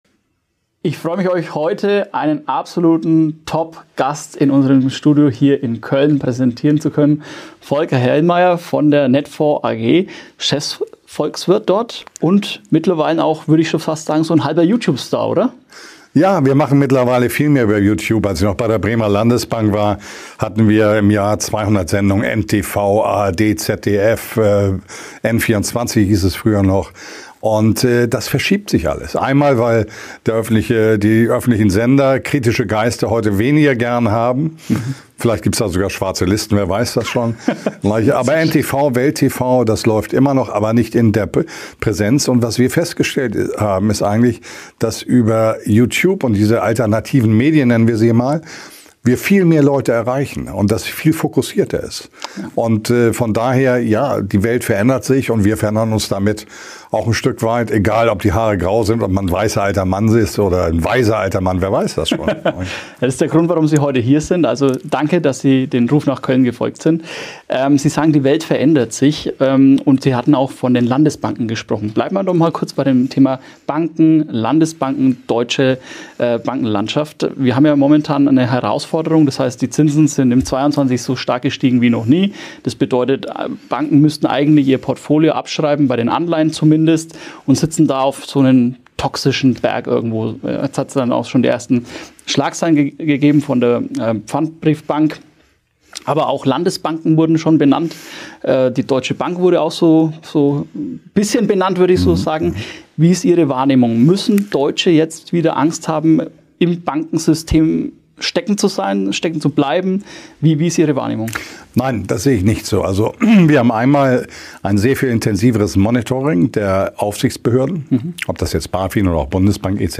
Beschreibung vor 1 Jahr In diesem Interview präsentiert Folker Hellmeyer, Chefvolkswirt bei Netfonds, seine Analysen zu aktuellen wirtschaftlichen und geopolitischen Herausforderungen. Er diskutiert die Bedeutung alternativer Medien, die Auswirkungen geopolitischer Spannungen im Nahen Osten, Russland und China sowie die Risiken ideologisch getriebener Energiepolitik in Deutschland.